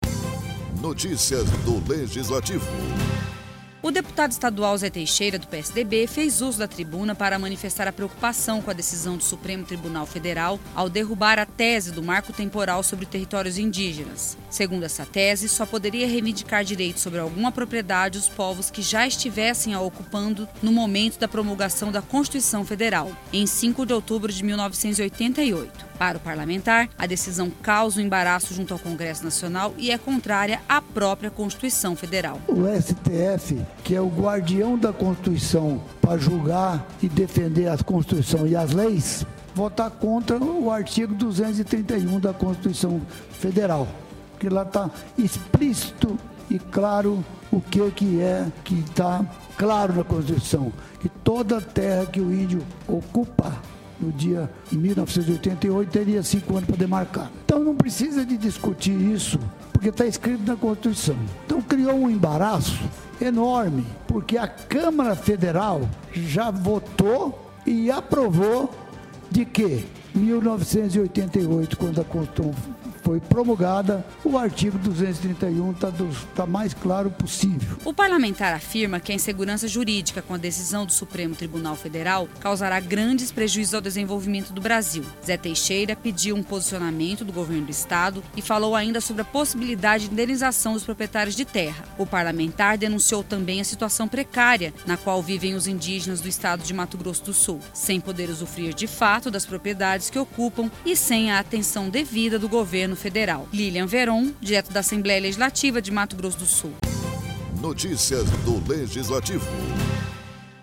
O deputado estadual Zé Teixeira do PSDB fez uso da tribuna para manifestar a preocupação com a decisão do Supremo Tribunal Federal (STF) ao derrubar a tese do Marco Temporal sobre territórios indígenas, agravando a insegurança jurídica.